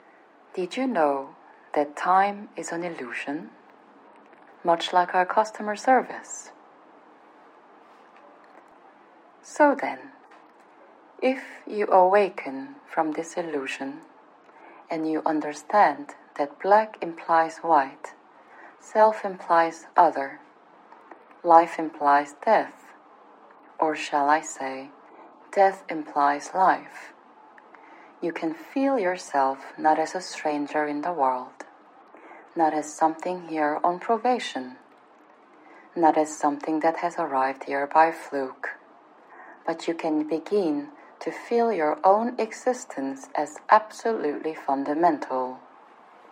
Operator_filtered14.mp3